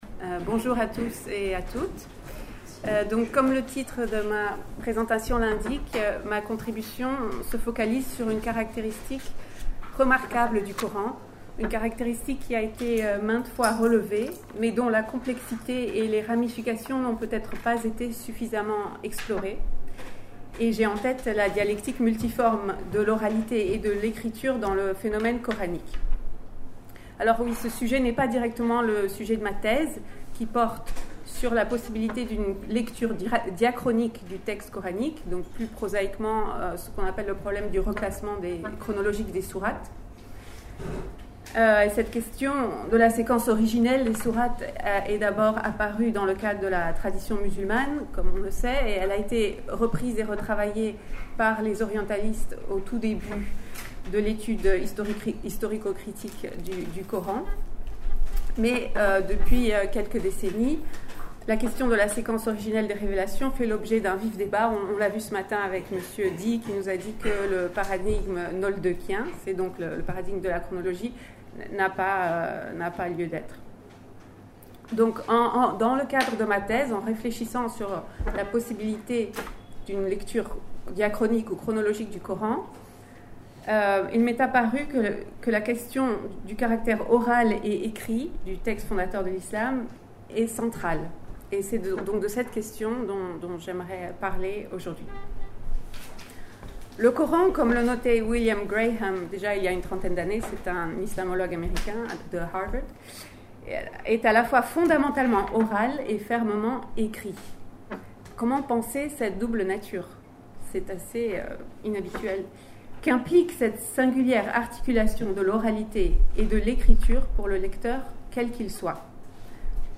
Doctorante à l’Université Paris- Sorbonne Accédez à l'intégralité de la conférence en podcast audio dans l'onglet téléchargement